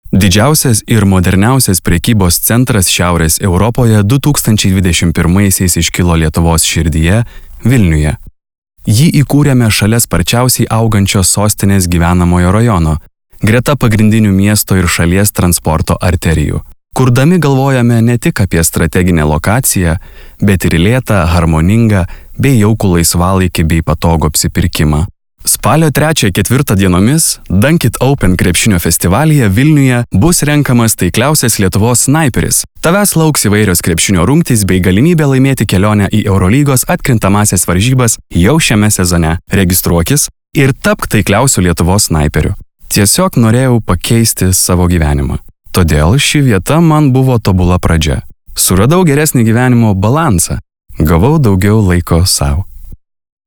Diktoriai